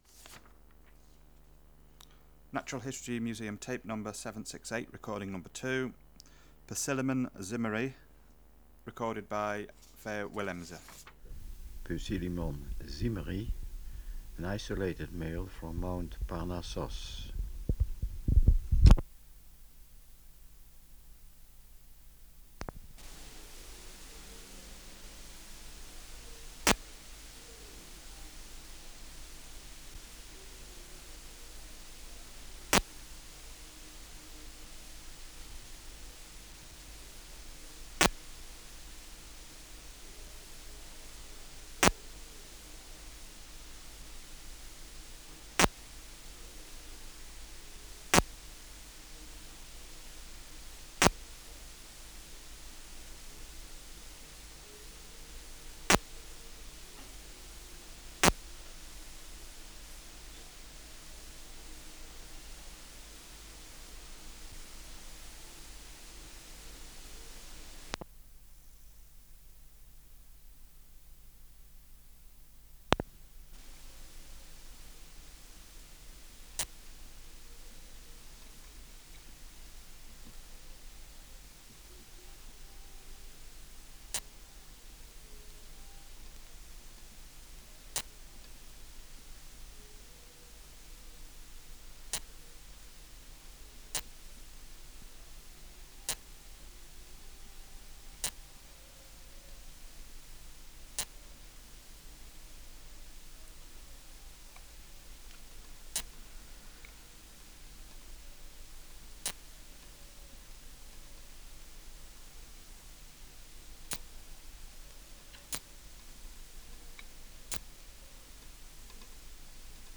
Natural History Museum Sound Archive Species: Poecilimon (Poecilimon) zimmeri
Air Movement: Nil
Extraneous Noise: Distant disco
Microphone & Power Supply: AKG D202E (LF circuit off)